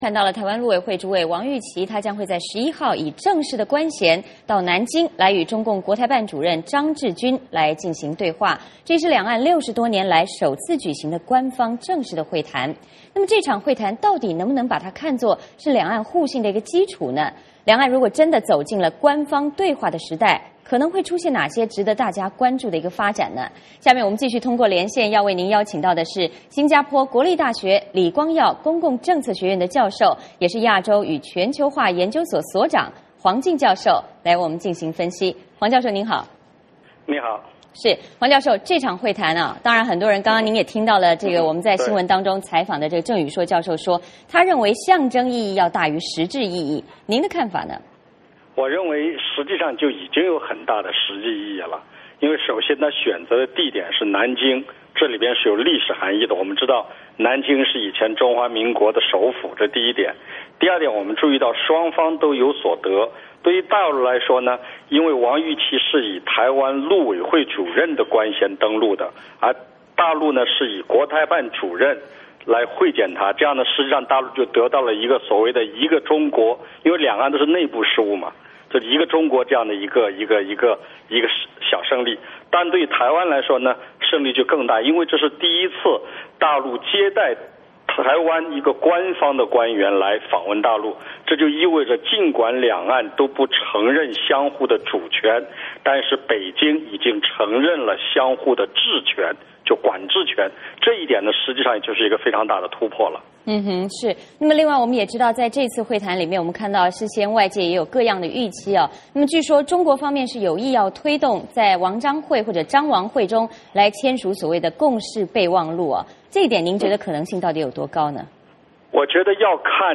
美国之音专访